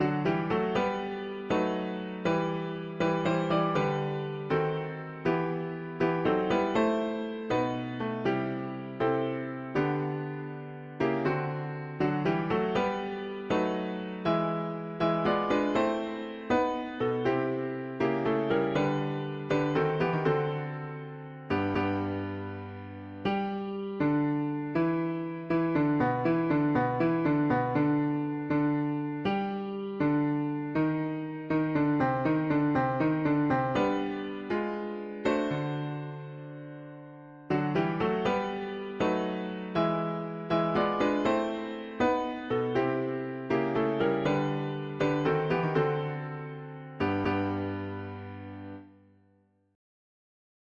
Key: G major